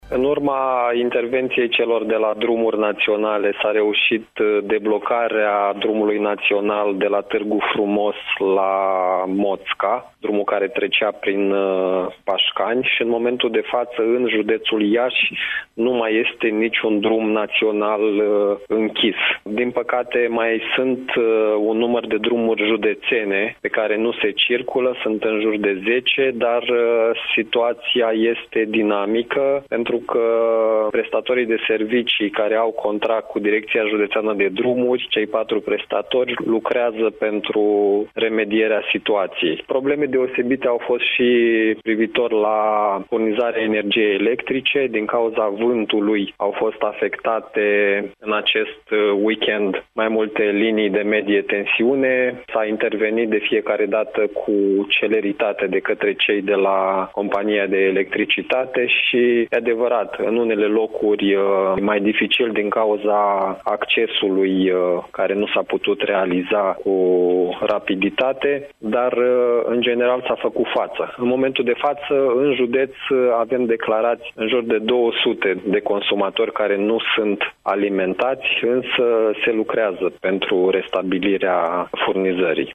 Prefectul de Iași, Marian Grigoraș, ÎN DIRECT la Radio Iași – Tema Zilei